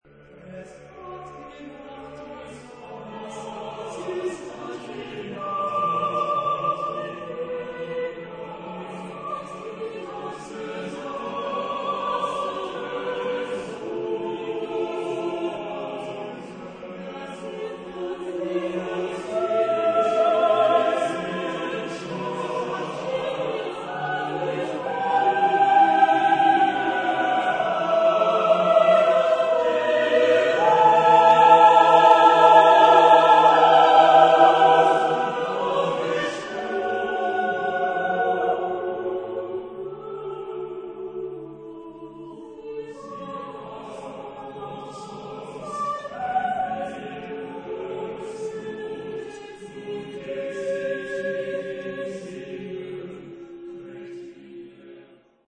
Genre-Style-Forme : contemporain
Type de choeur : 4S-4A-4T-4B  (16 voix mixtes )